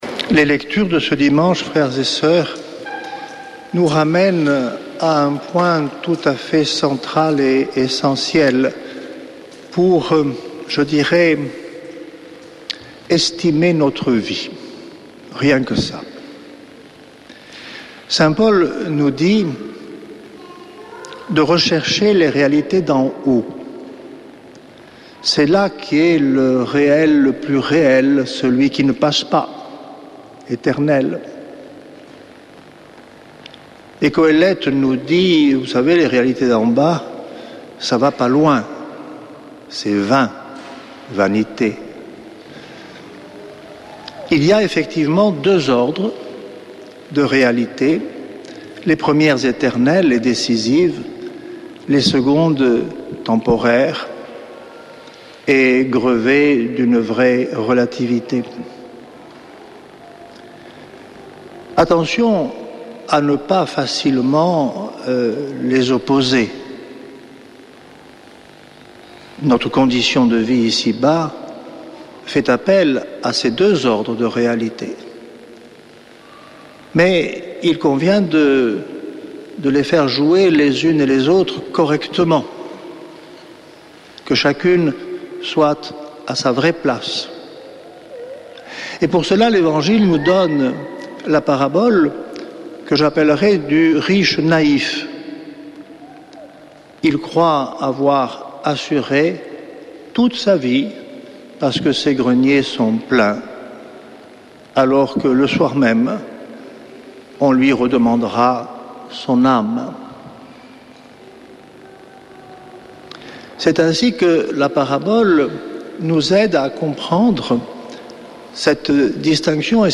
Homélie depuis le couvent des Dominicains de Toulouse du 03 août
Frères de la communauté